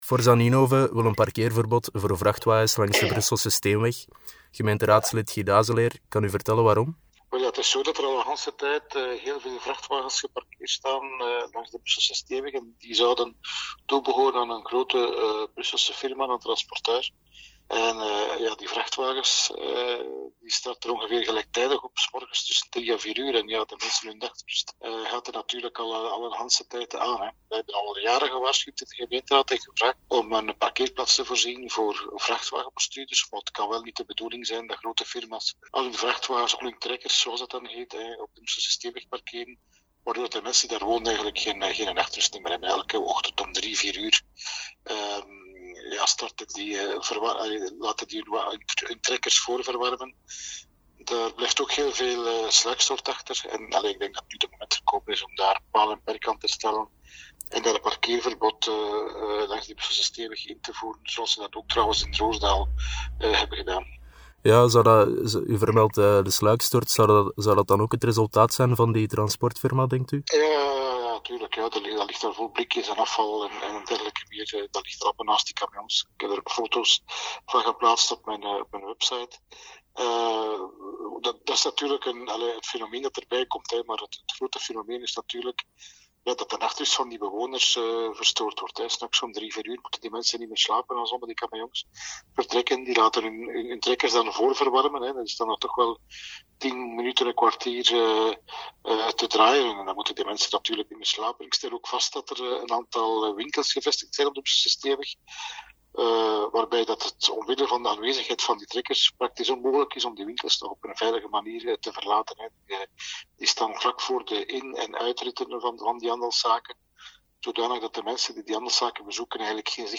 Beluister hieronder het interview met gemeenteraadslid Guy D’haeseleer:
interview-Guy-Dhaeseleer_volledig.mp3